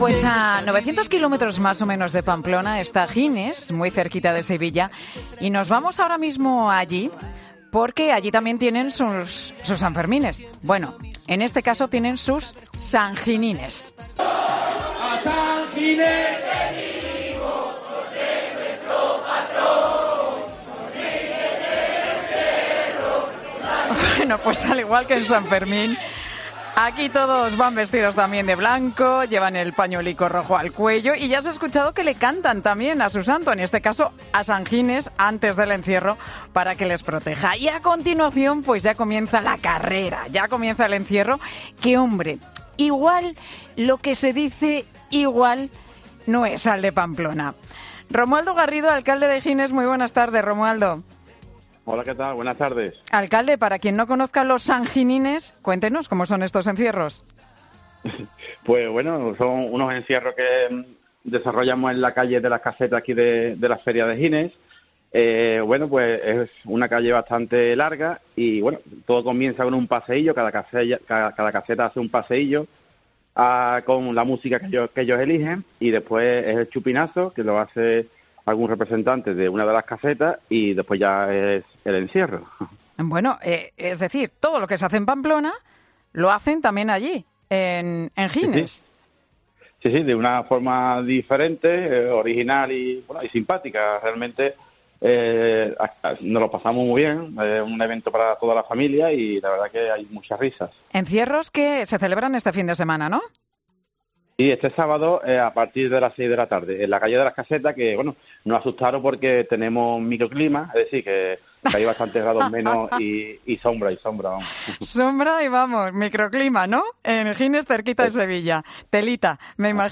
El alcalde de Gines, Romualdo Garrido, ha detallado en 'Mediodía COPE' los actos que tienen lugar durante esta curiosa fiesta en el pueblo sevillano